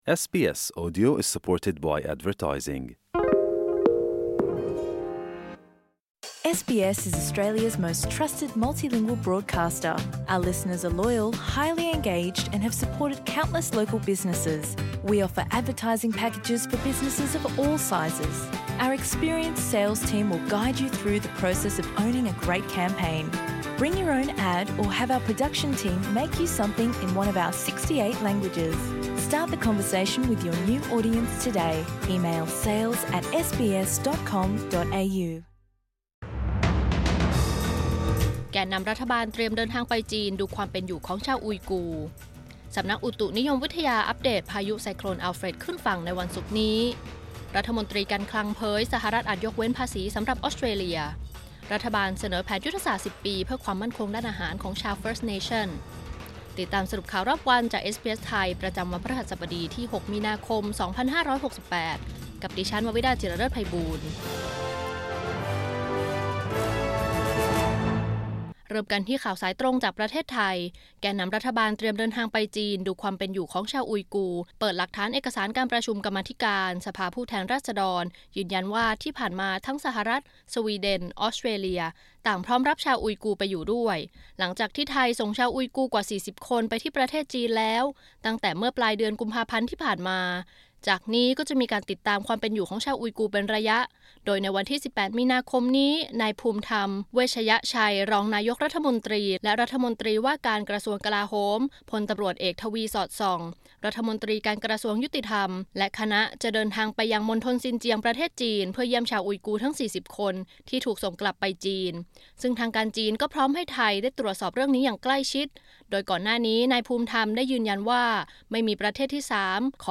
สรุปข่าวรอบวัน 6 มีนาคม 2568